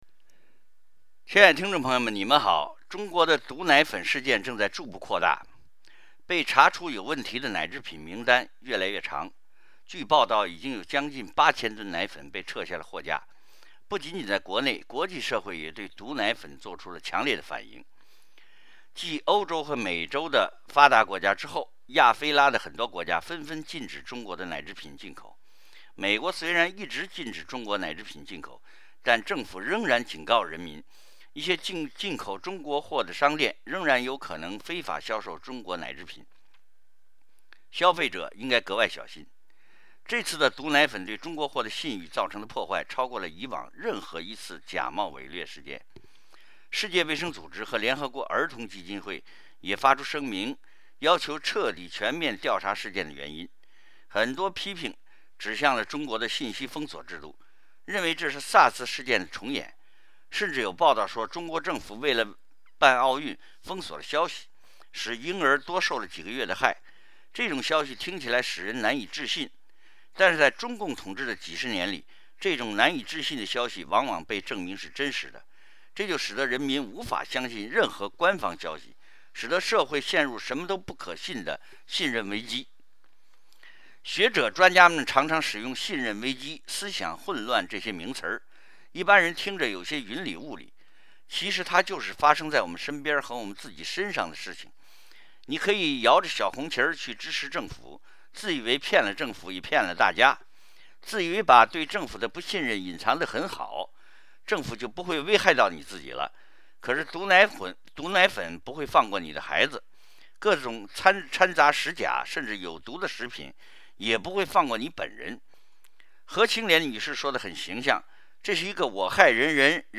Wei Jingsheng's commentary on Radio Free Asia about CCP Found a Problem in Defining Its Own Role